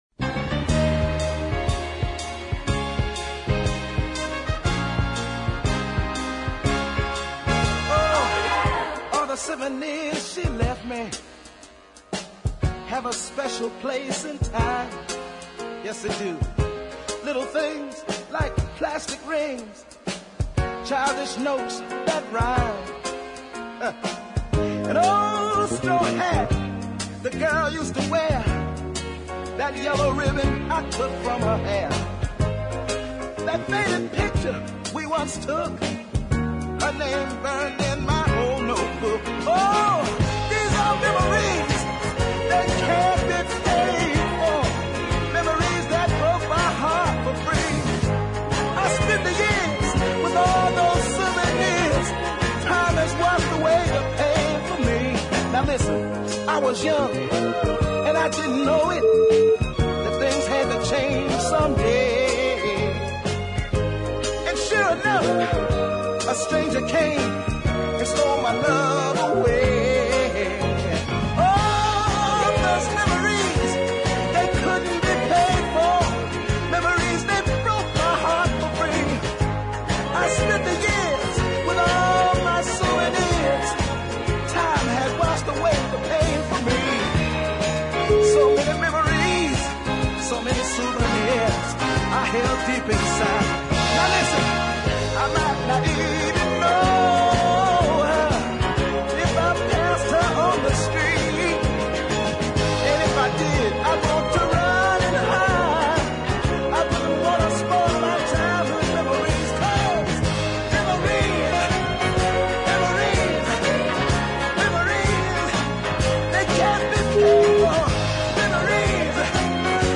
authentic southern soul
fine baritone voice